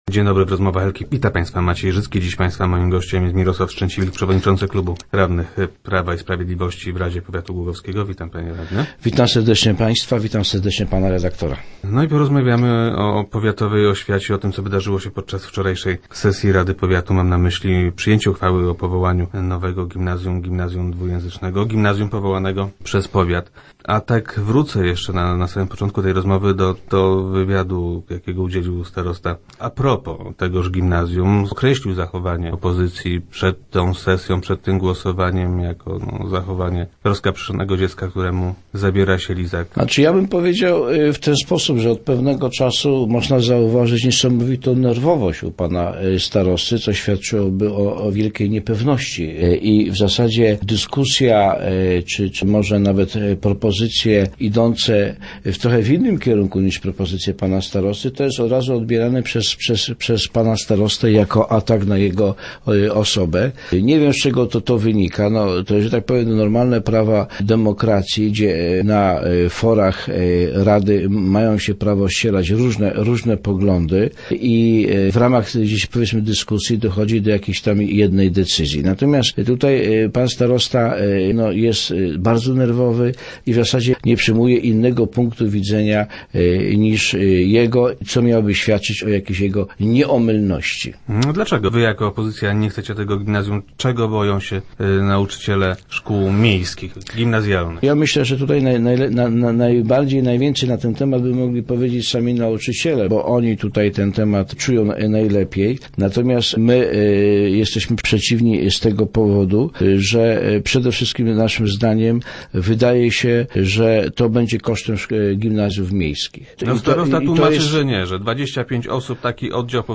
- Zabrakło konkretnych konsultacji w tej sprawie – twierdzi Mirosław Strzęciwilk, przewodniczący klubu radnych powiatowych PiS, który był gościem czwartkowych Rozmów Elki.